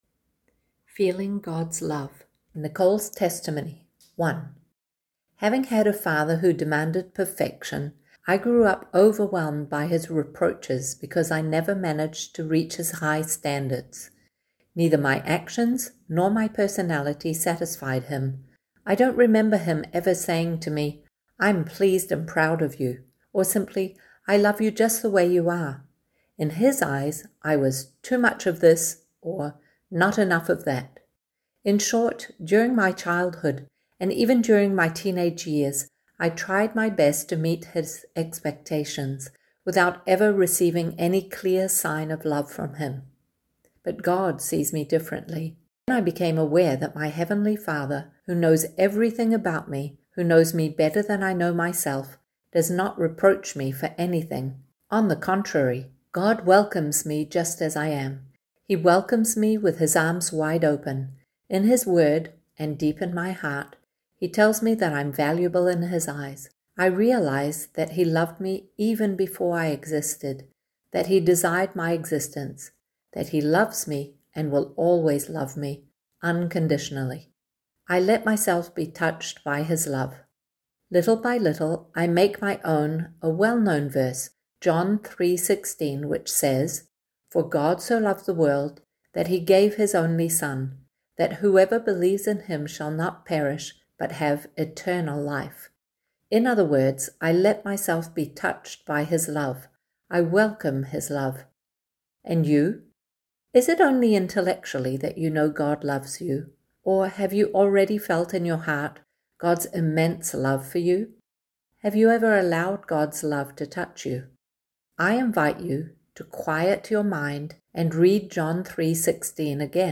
Audios, Testimonies